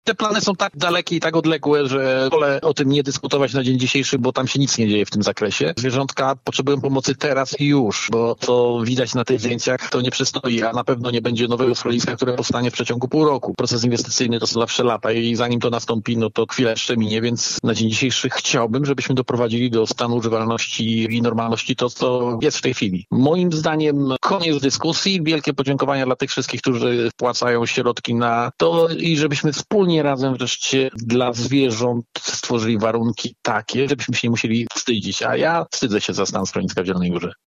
Janusz Kubicki odniósł się też do planów budowy nowego schroniska: